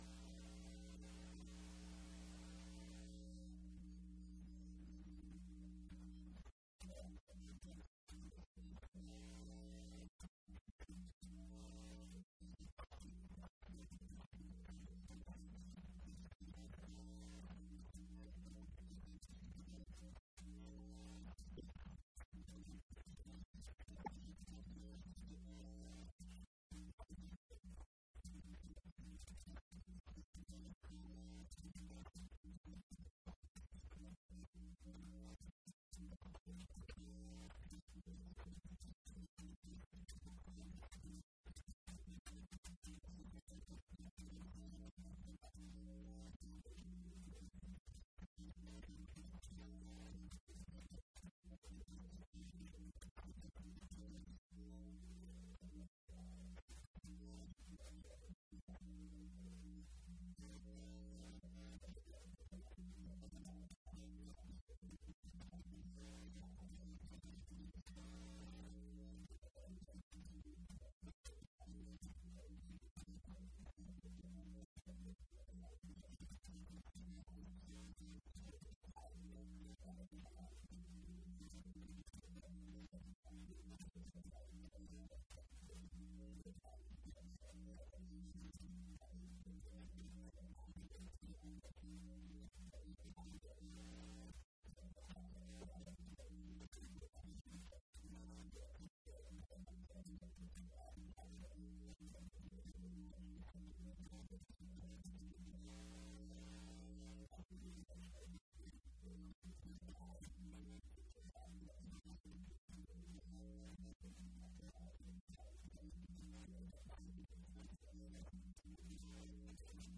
Colloquium